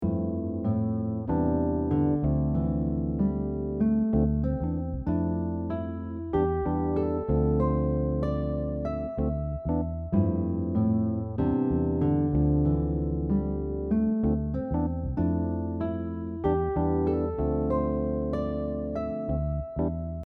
In example 3, we’re using the connected pentatonic shape 1 over a new chord progression.